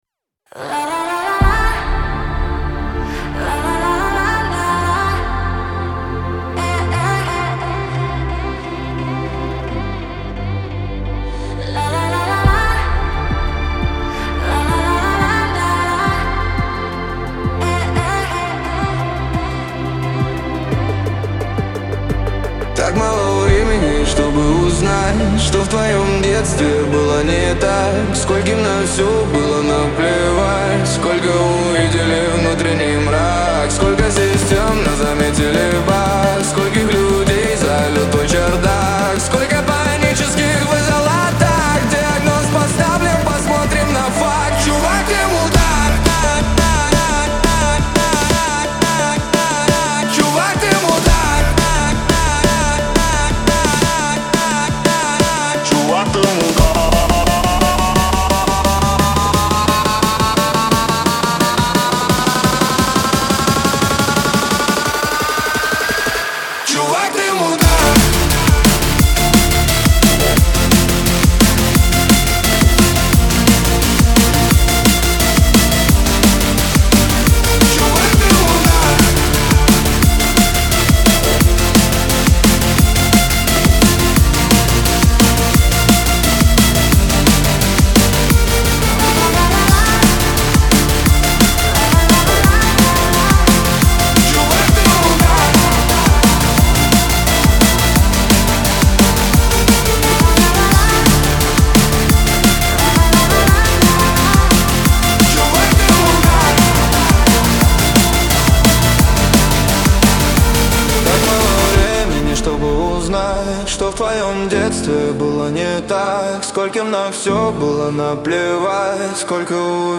Трек размещён в разделе Русские песни / Электронная музыка.